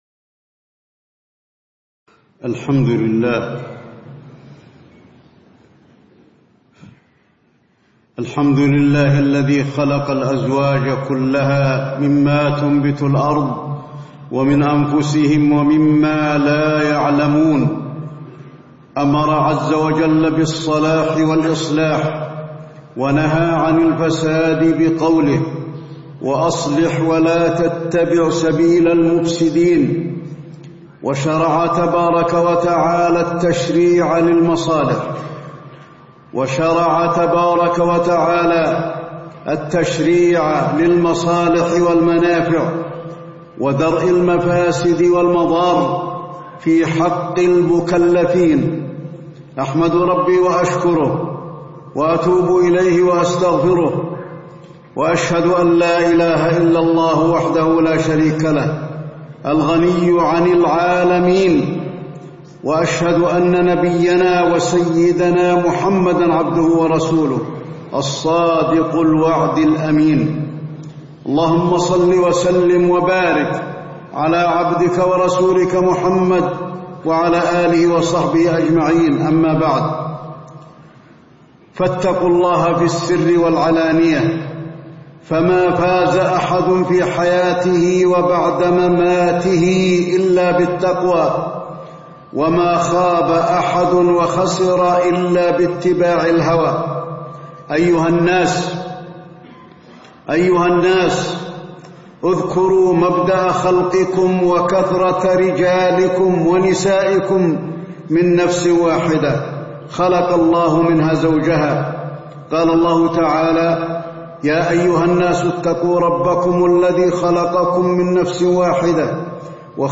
تاريخ النشر ٣ جمادى الأولى ١٤٣٧ هـ المكان: المسجد النبوي الشيخ: فضيلة الشيخ د. علي بن عبدالرحمن الحذيفي فضيلة الشيخ د. علي بن عبدالرحمن الحذيفي الحقوق الزوجية وأسباب الطلاق